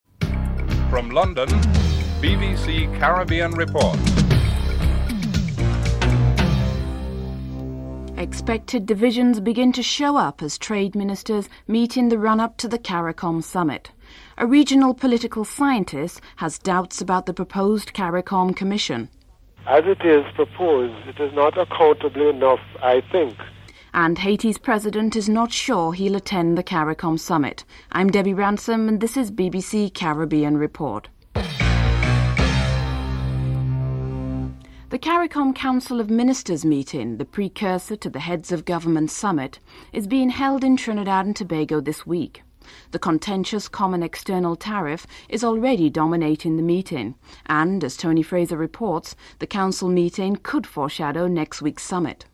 Report ends abruptly.